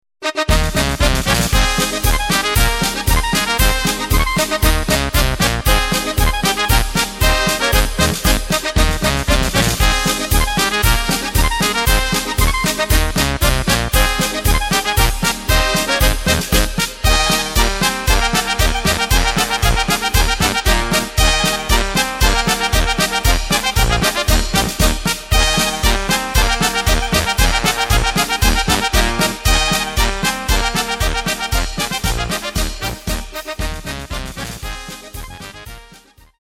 Takt:          2/4
Tempo:         116.00
Tonart:            Ab